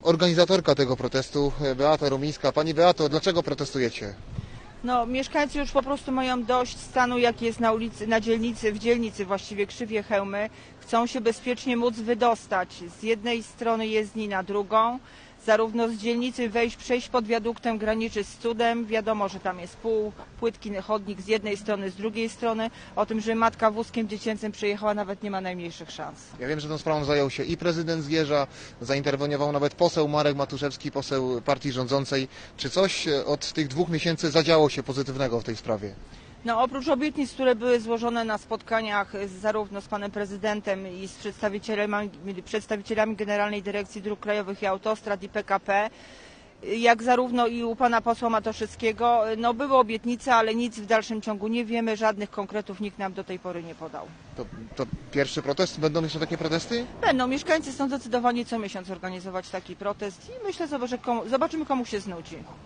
Protest, blokada drogi